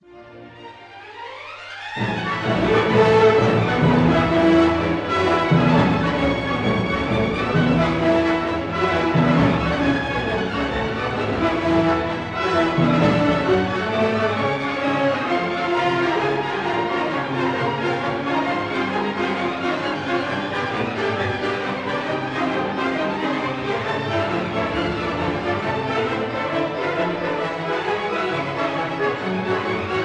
1956 live performance